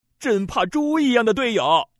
Index of /guizhou_ceshi_0/update/11363/res/sfx/ddz/man/